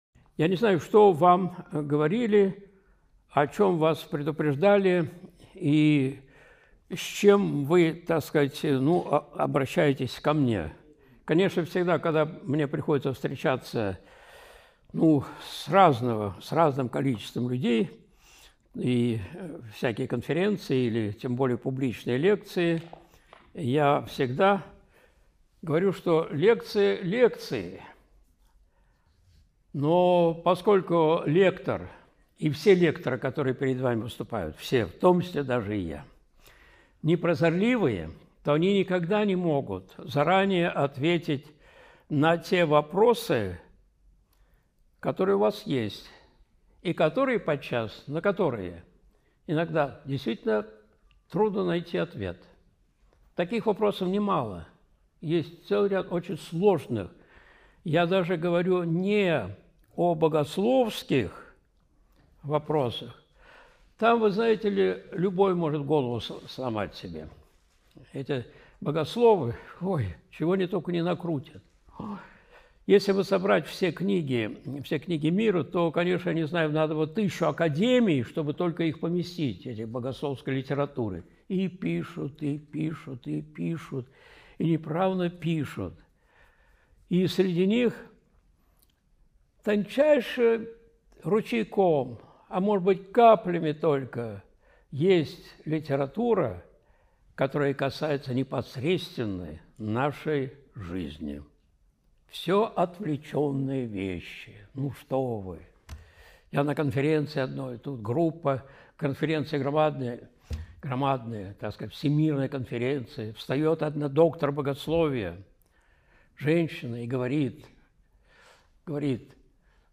Жестокое рабство — конец европейской свободы (МДА, встреча с духовенством, 13.08.2024)
Видеолекции протоиерея Алексея Осипова